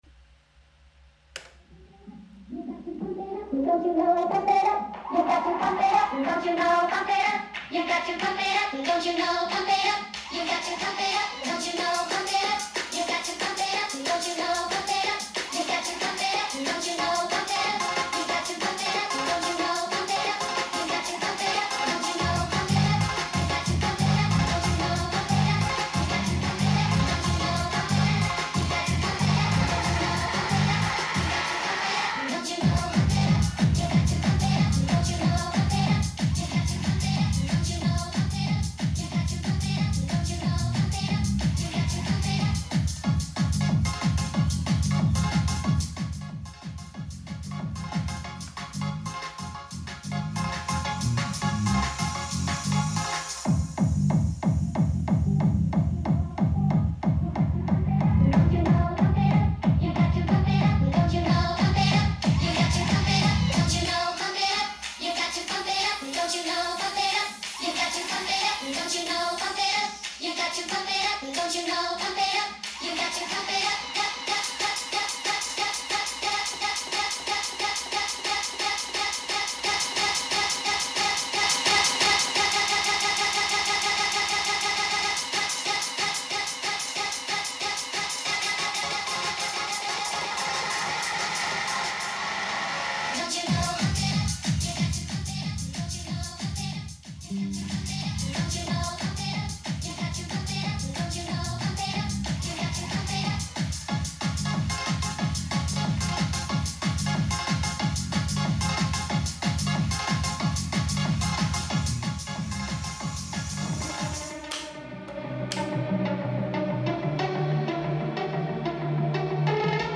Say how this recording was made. Selbst produziert, selbst gemixt – kein fremder Content.